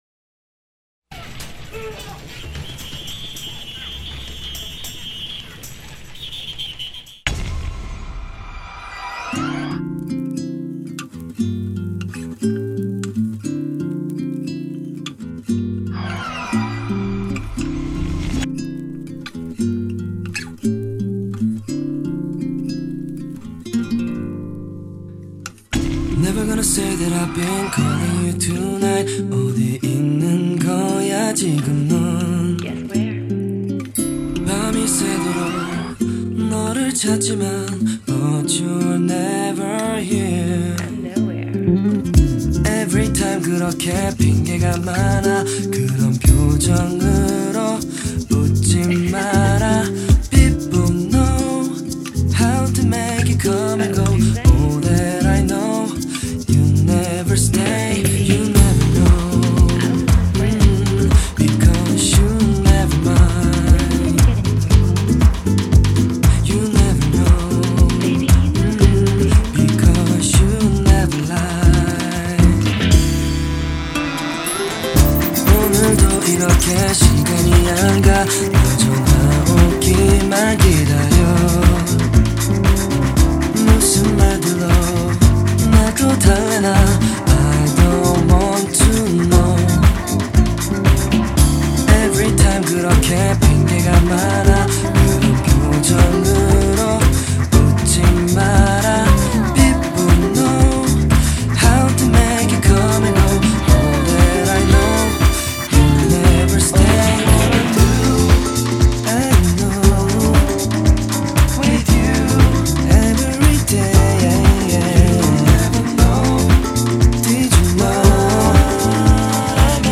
electronica band
the suave crooner
the sultry singer